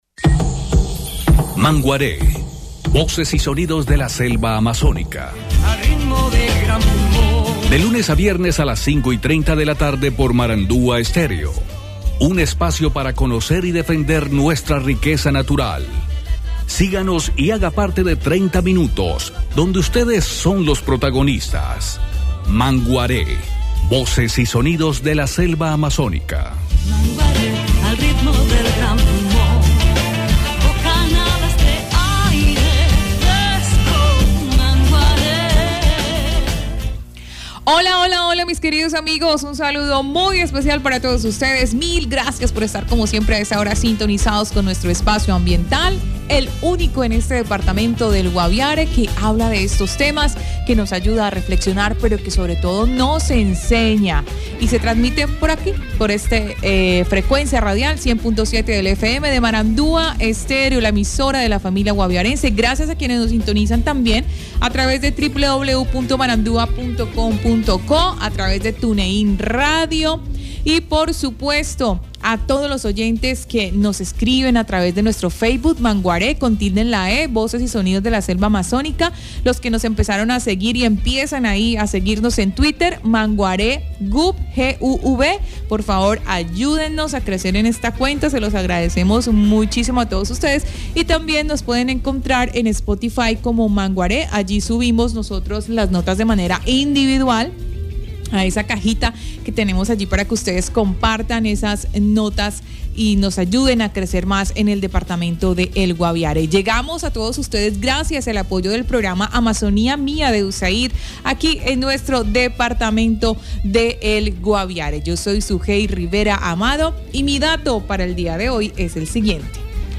El municipio de Calamar Guaviare, instaló la mesa de lucha contra la deforestación y se propone como escenario para para hablar de cambio climático en la Amazonía colombiana. Su alcalde, Geovanny Garcés, nos habla de estos y otros temas.